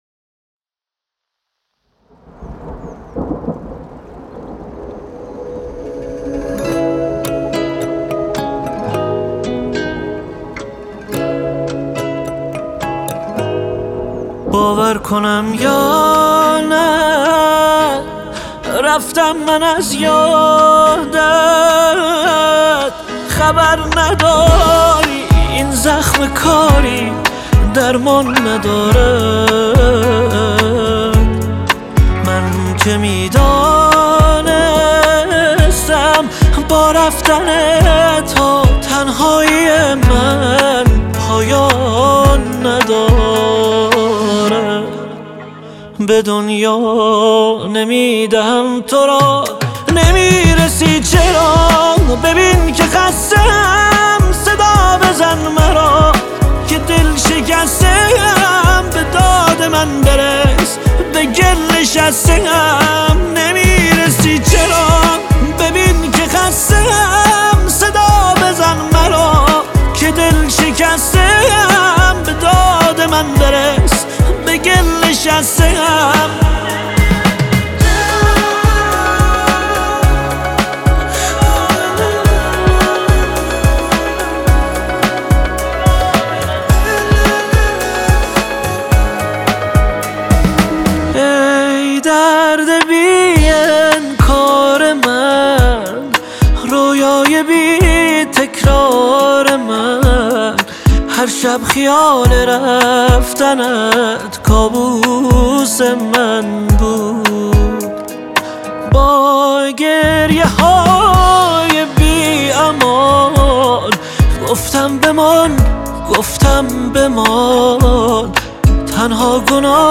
تک آهنگ
غمگین